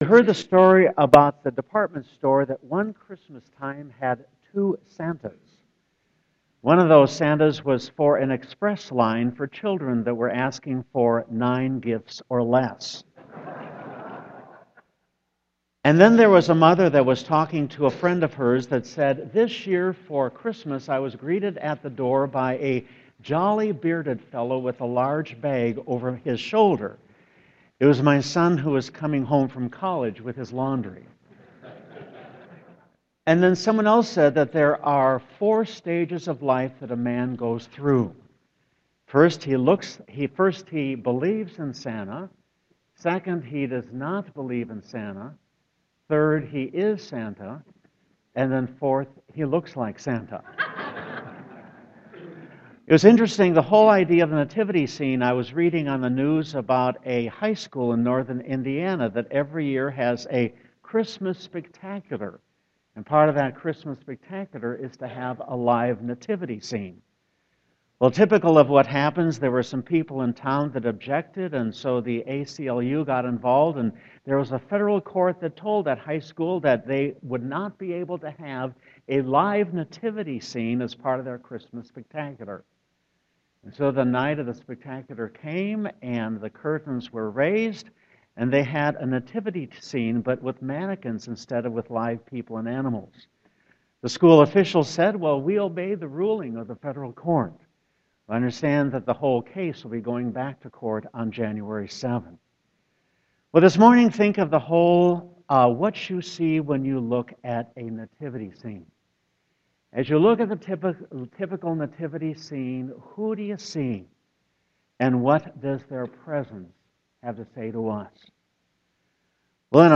Sermon 12.27.2015